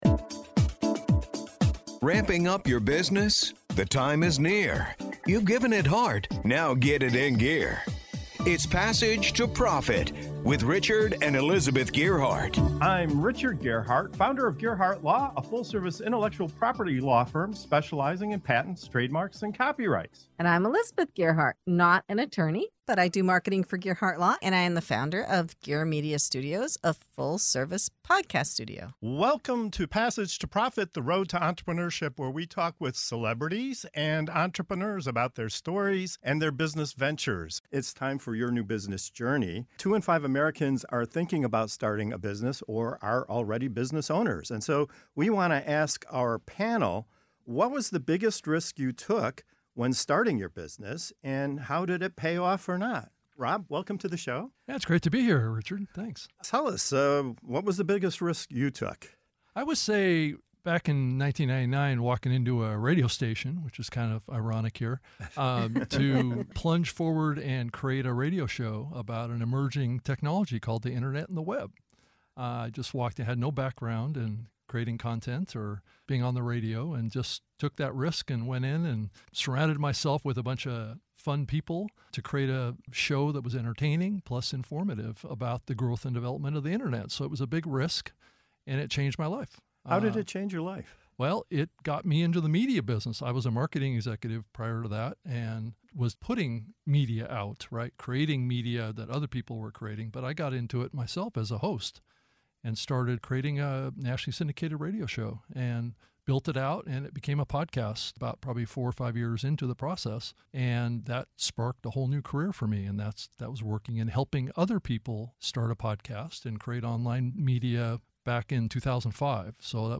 What does it really take to break away from the expected path and build something extraordinary? In this segment of "Your New Business Journey" on Passage to Profit Show, three entrepreneurs reveal the biggest gambles they took to chase their visions—from launching a tech radio show with zero experience, to ditching college and crossing continents to pursue a beauty empire, to failing multiple times before finally hitting entrepreneurial gold.